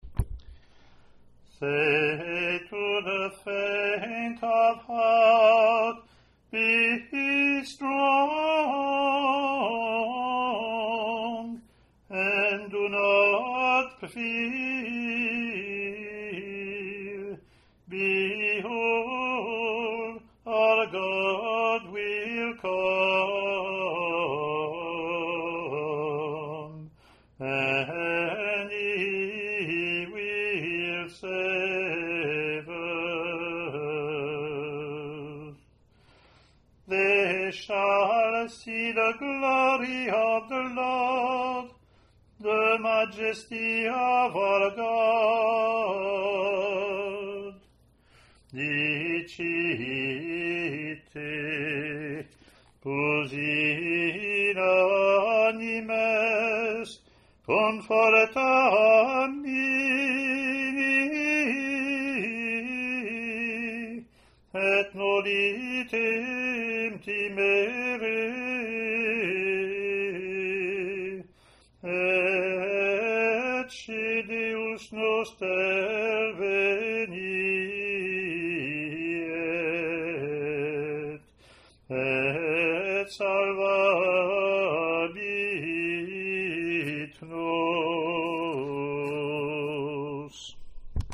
Click to hear Communion (
English antiphon – English verse – Latin antiphon + verses)